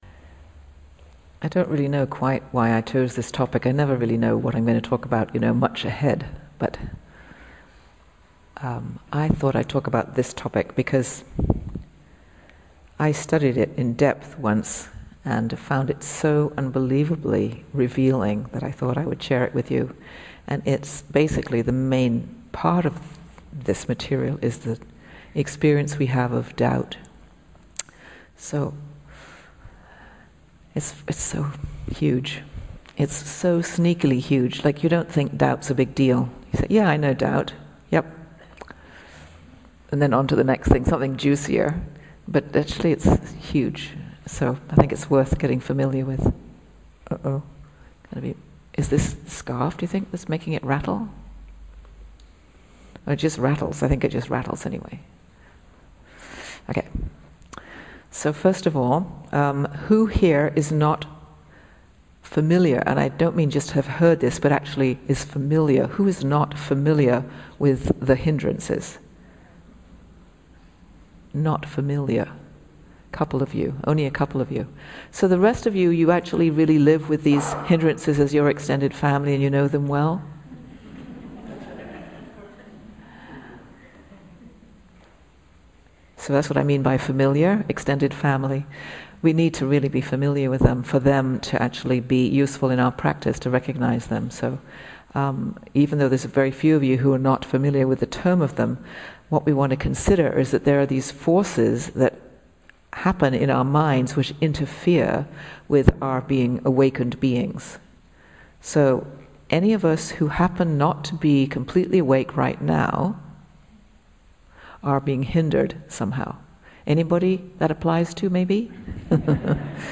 2018-06-29 Venue: Seattle Insight Meditation Center Series